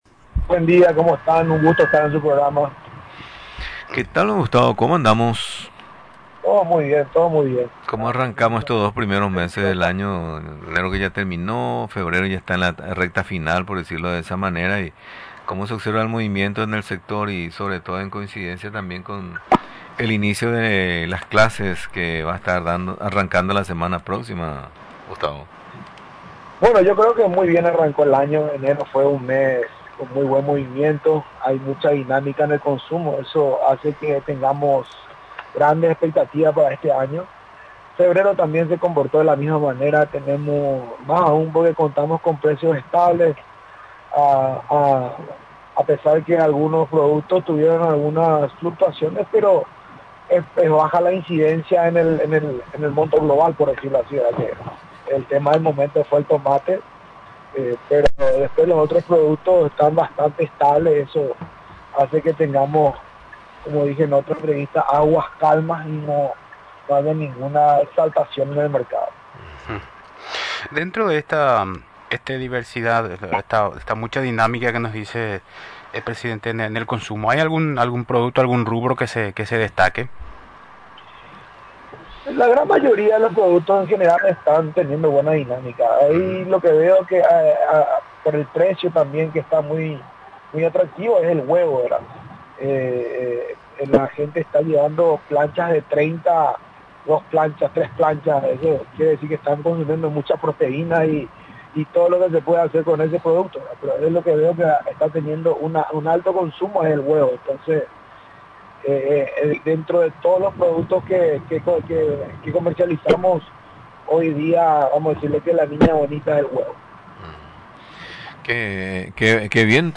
Durante la entrevista se refirió sobre los detalles que dialogó con el ministro de Agricultura y Ganadería el martes pasado. Finalmente, destacó que tras la reunión, existen muy buenas perspectivas con respecto a la rueda de negocios entre los productos y CAPASU.